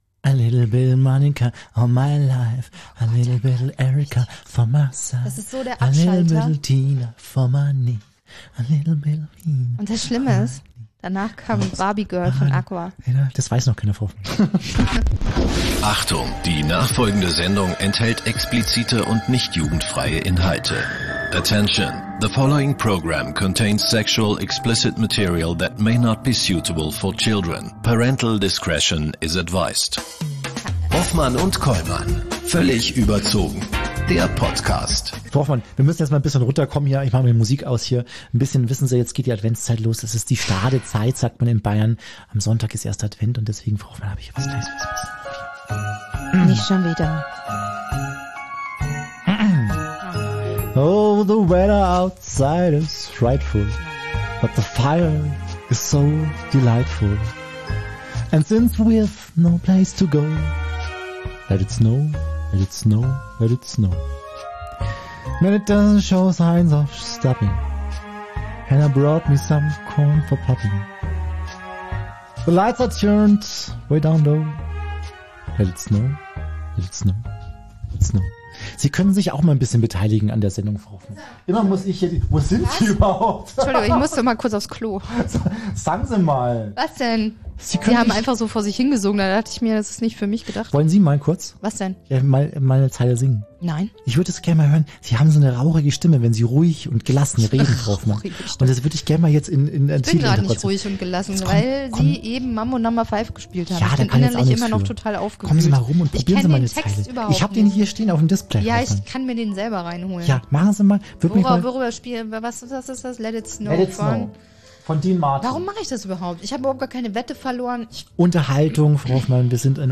Gast: Moritz Bleibtreu (Schauspieler) In der 67ten Folge von "Völlig überzogen" sprechen wir über den dunkelsten Tag der deutschen Pressegeschichte. Das Nachrichtenmagazin „stern“ stellt die Hitler-Tagebücher vor.
Wie so ein Fake überhaupt möglich sein konnte, darüber sprechen wir mit Schauspieler Moritz Bleibtreu.